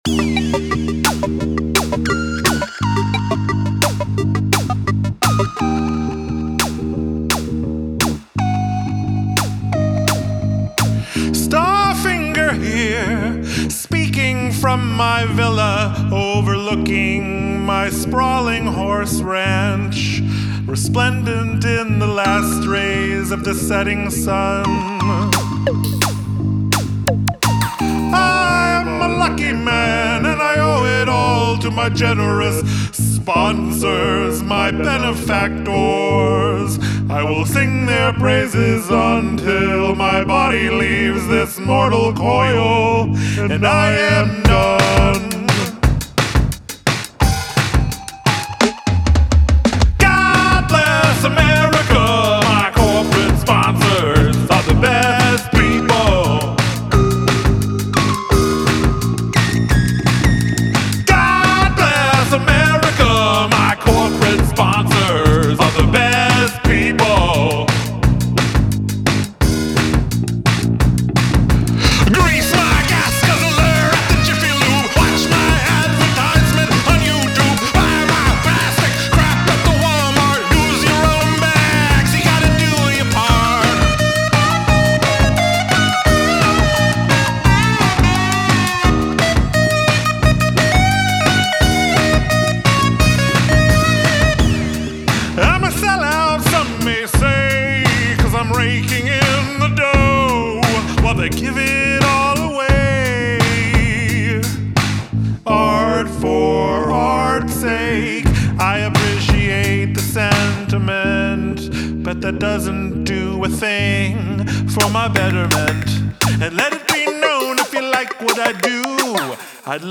The bass and drum in the chorus are pretty great.